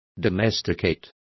Complete with pronunciation of the translation of domesticate.